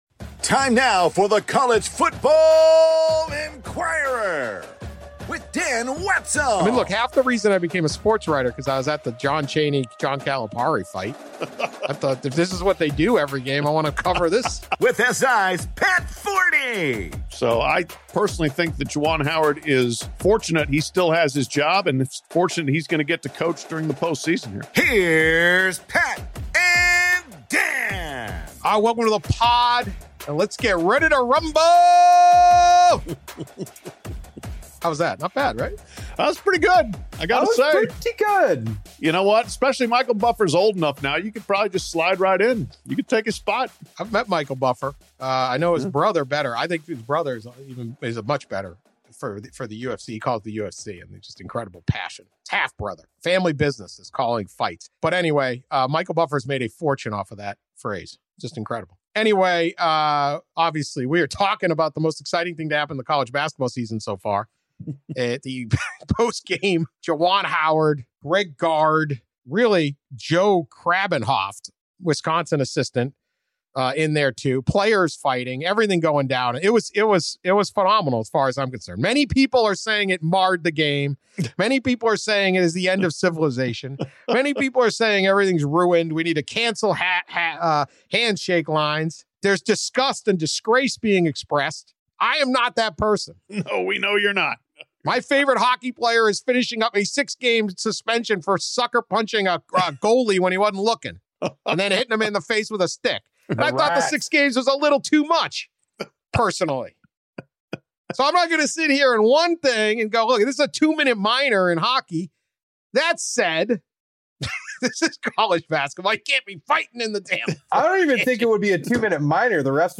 Dan Wetzel and SI’s Pat Forde react to Michigan suspending Juwan Howard after the fracas with Wisconsin coaches and players.